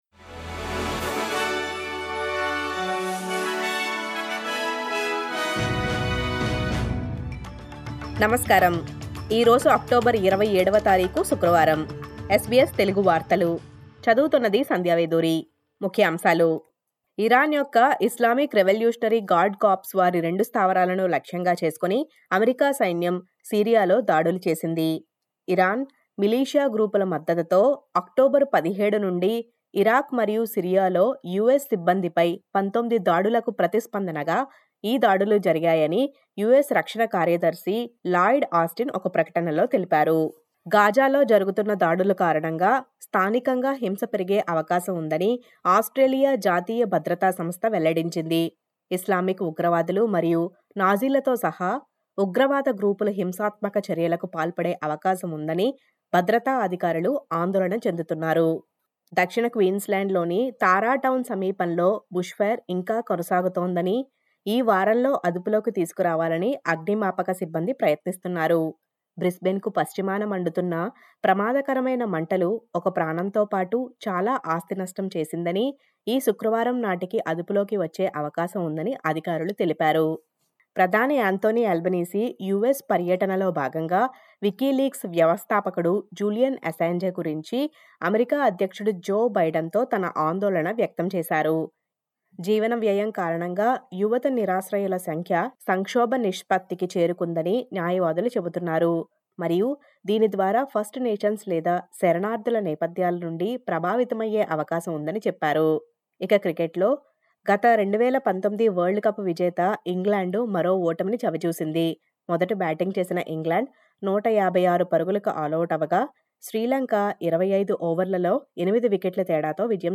SBS తెలుగు వార్తలు.